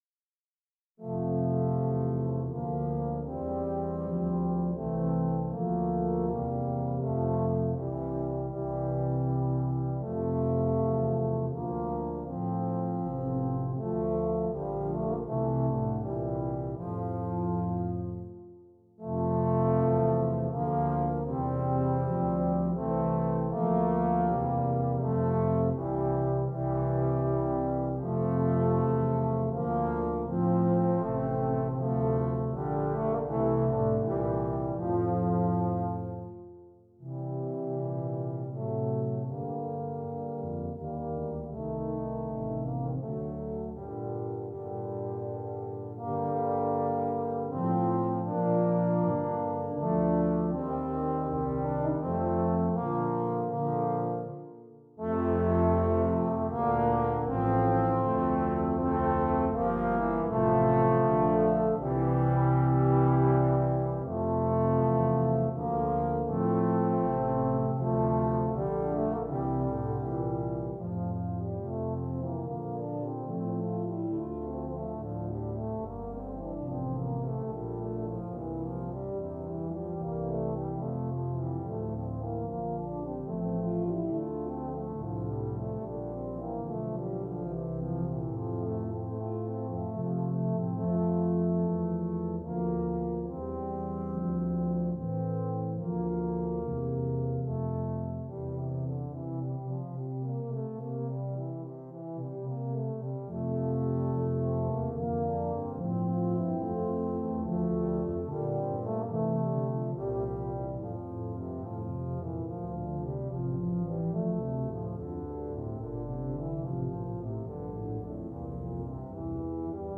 2 Euphoniums, 2 Tubas